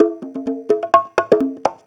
Conga Loop 128 BPM (25).wav